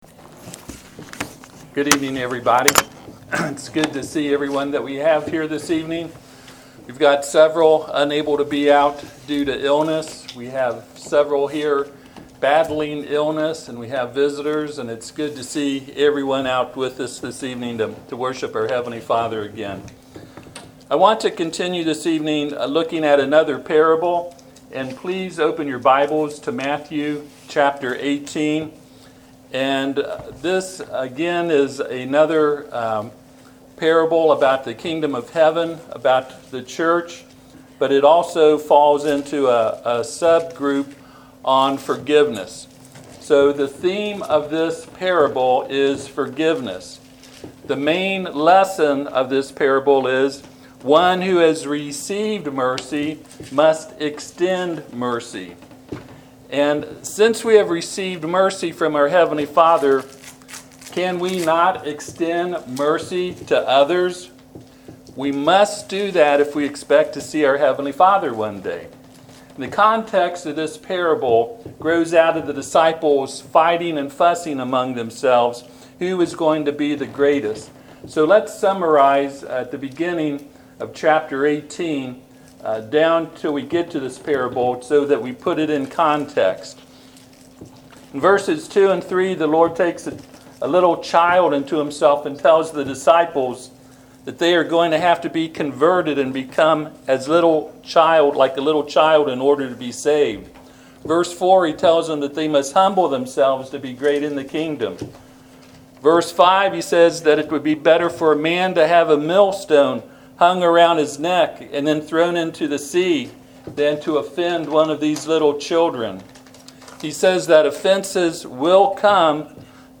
Passage: Matthew 18:21-35 Service Type: Sunday PM « Sermon on the Mount